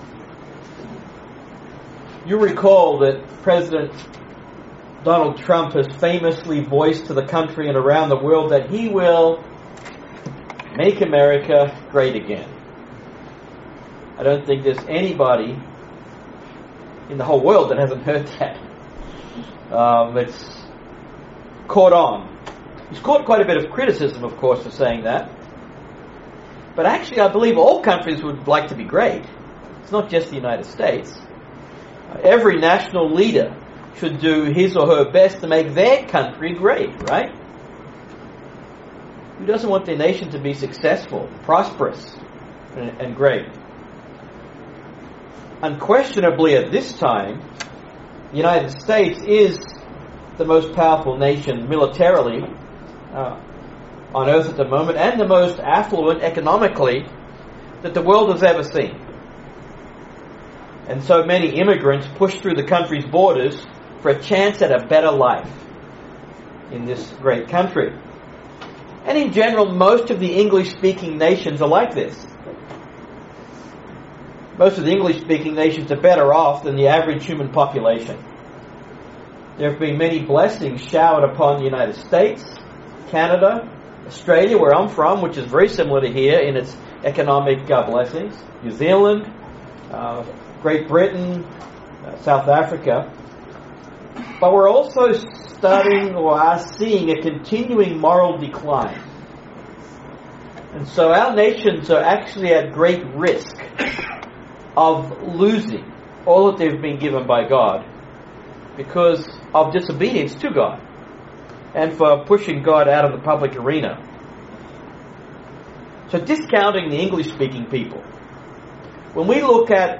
Excellent Sermon on the Phrase "Make America Great Again" or what ever country you want to insert. How can we make the world great again? Was the world ever great?